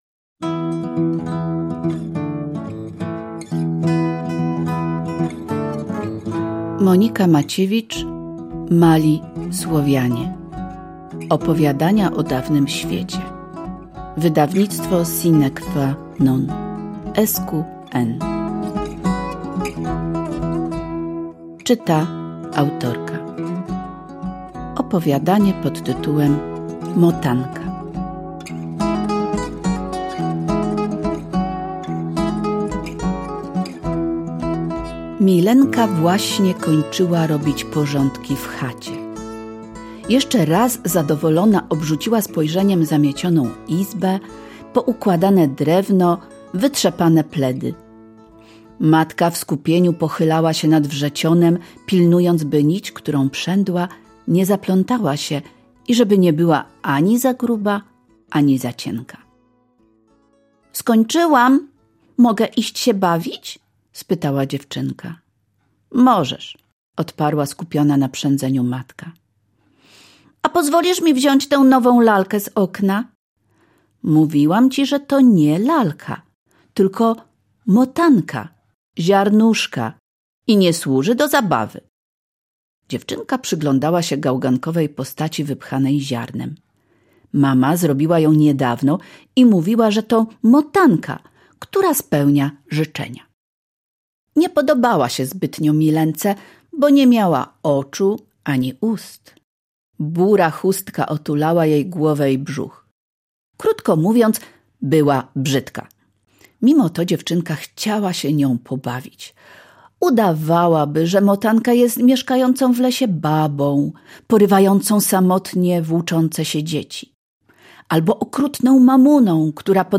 Tę historię wyemitowaliśmy premierowo w naszej audycji dla dzieci „Zamkolandia”.